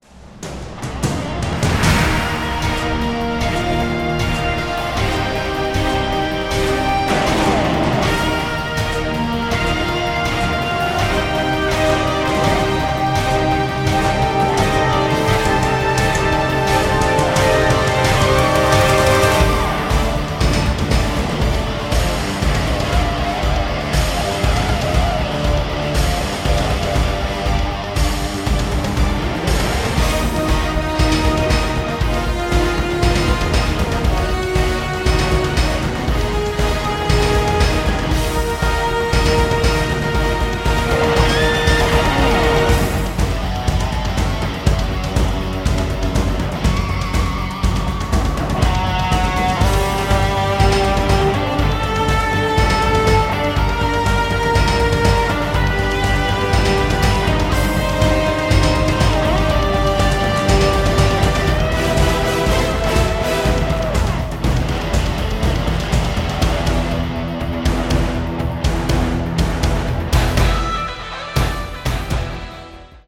SCORE PRESENTATION